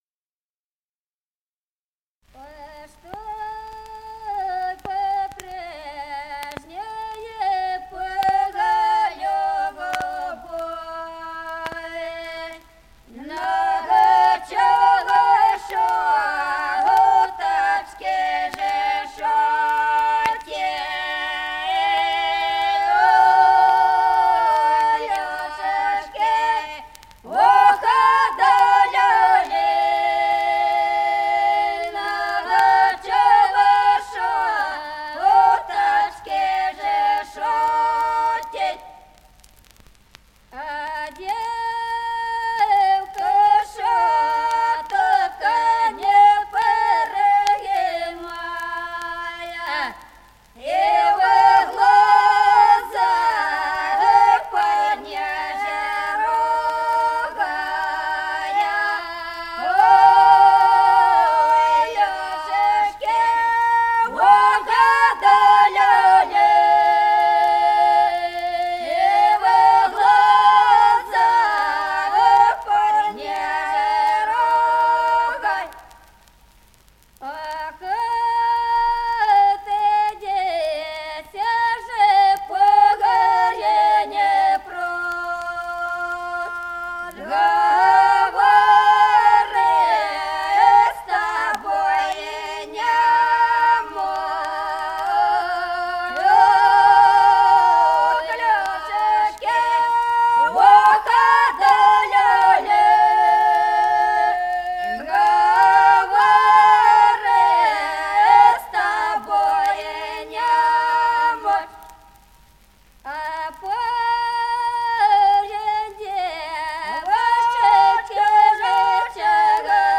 Записано в Москве весной 1966 г., с. Остроглядово.